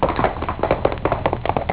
Horserun
HORSERUN.wav